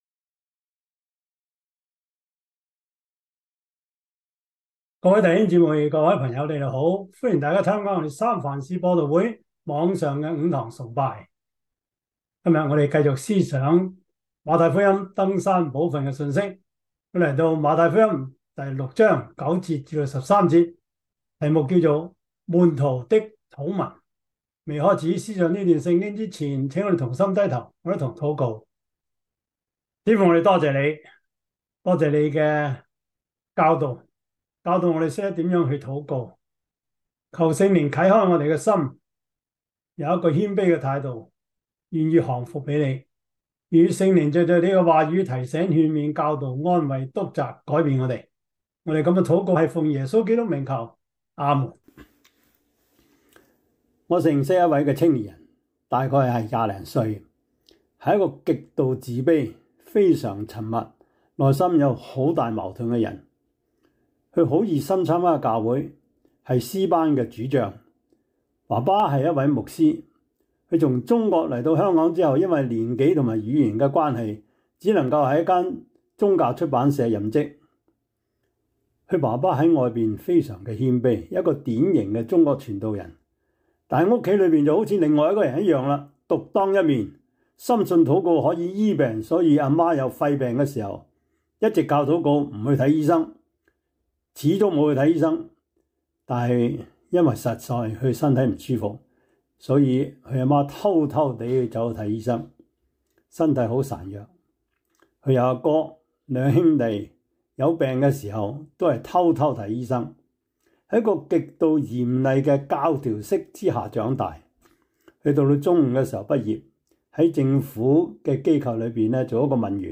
馬太福音 6:9-13 Service Type: 主日崇拜 馬太福音 6:9-13 Chinese Union Version